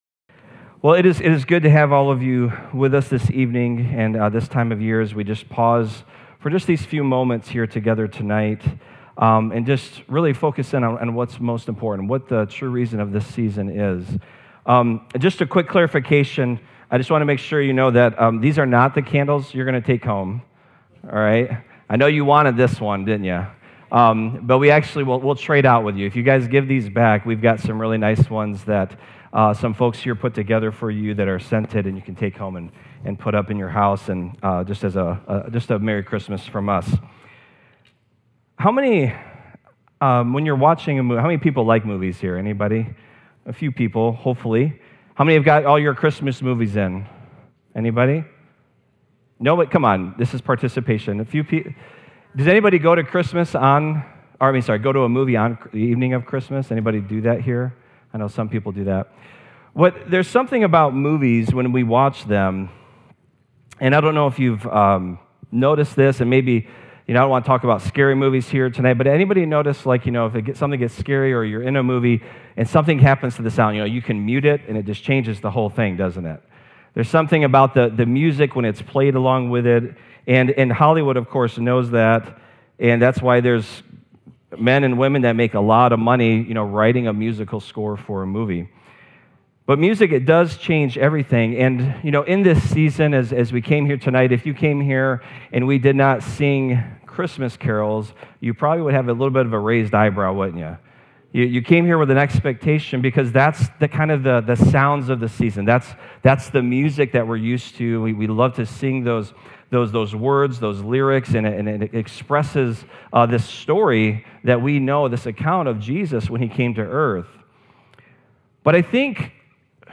2025 Christmas Eve.mp3